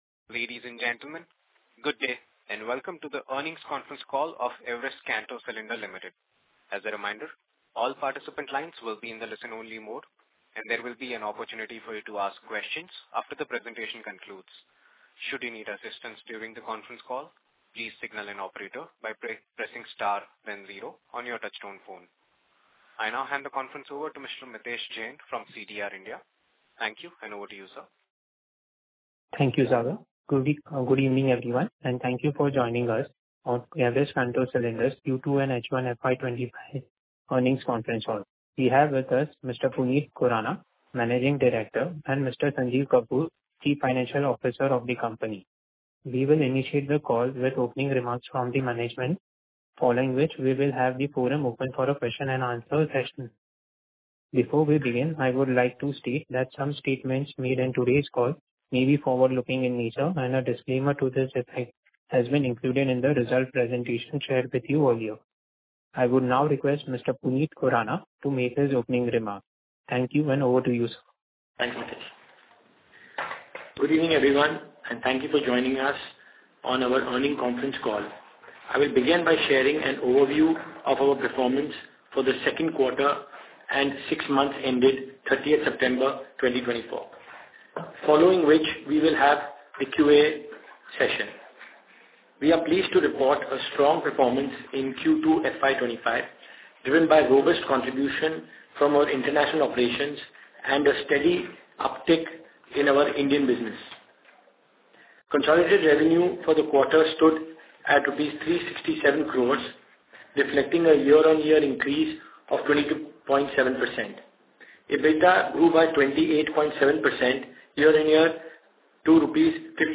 Concalls
EKC-Q2-FY25-Concall-Audio.mp3